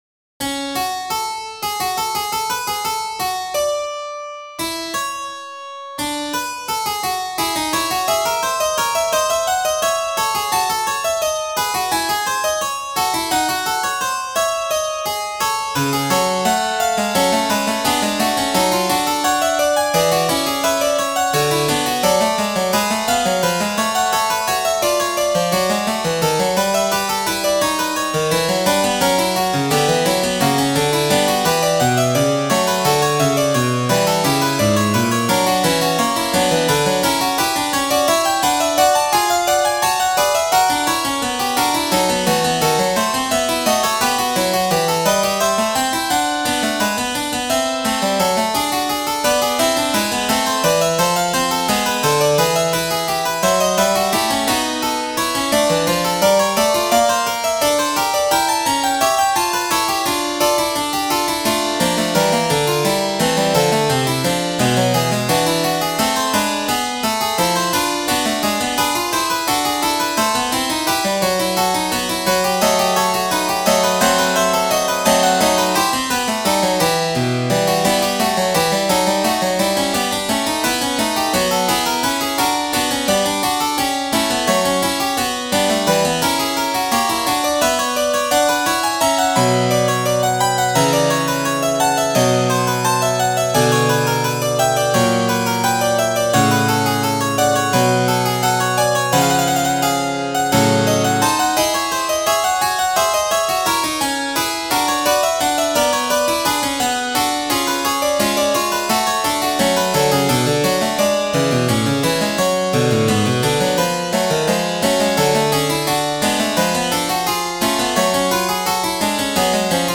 Fugue in F-sharp minor No.4 - Piano Music, Solo Keyboard - Young Composers Music Forum
Fugue in F-sharp minor No.4